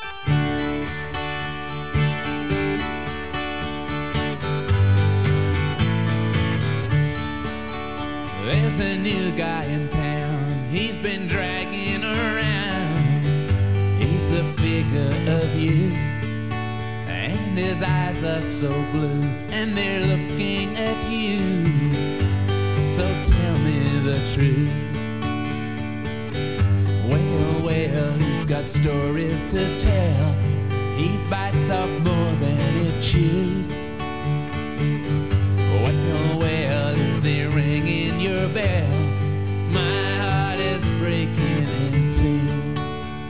vocal, acoustic guitar
harmony vocal, acoustic guitar
tambourine
bass
harpsichard, harmonium
shaker
penny whistle